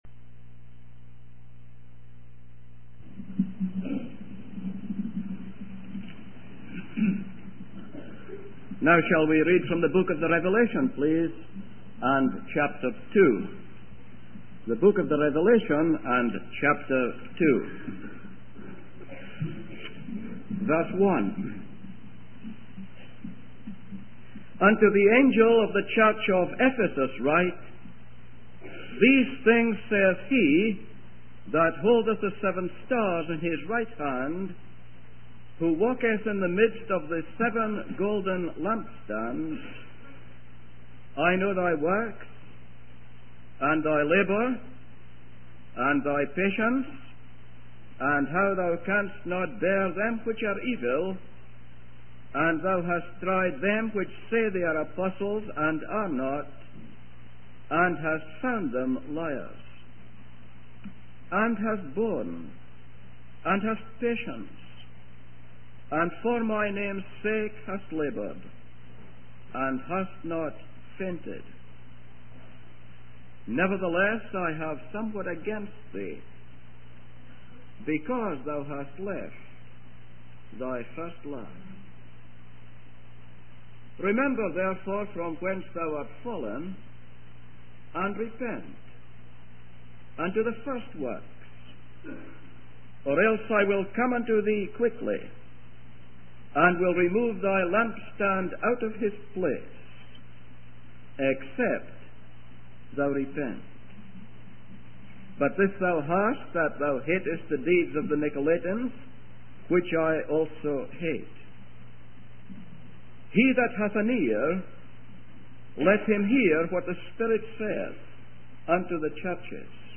In this sermon, the speaker discusses the book of Revelation and its division into three parts: the things which have been seen, the things which are, and the things which shall be. The speaker emphasizes that we are currently in a morally dark world and as believers, we have a responsibility to be lightbearers. The sermon then focuses on the letter to the church in Ephesus, highlighting the Lord's character, his message to the church, and his promise to the overcomers.